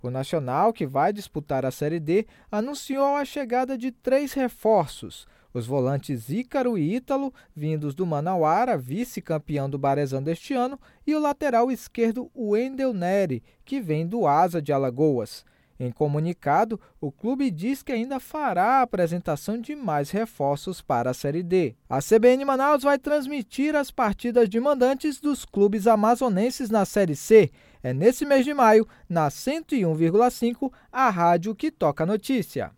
Nome do Artista - BOLETIM ESPORTE - NACIONAL 260423.mp3